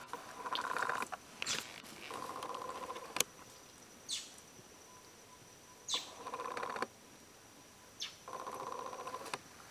Rufous Gnateater (Conopophaga lineata)
Life Stage: Adult
Location or protected area: Bio Reserva Karadya
Condition: Wild
Certainty: Recorded vocal